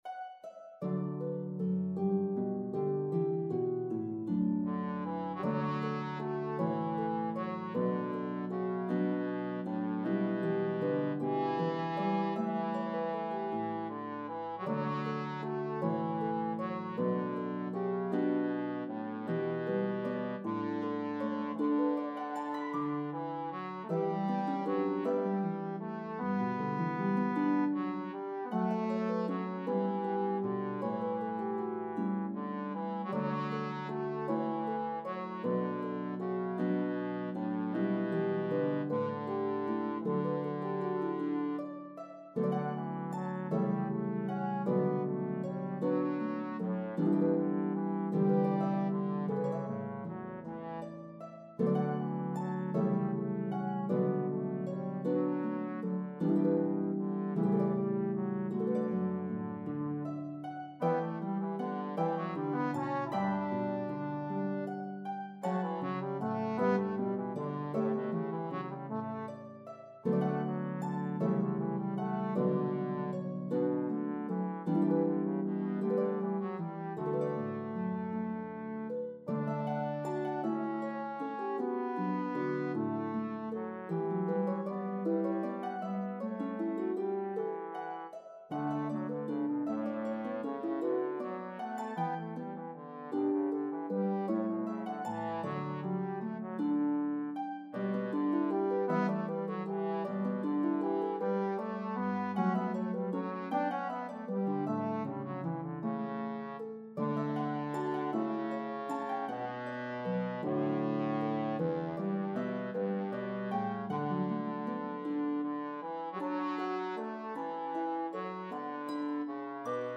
Harp and Trombone version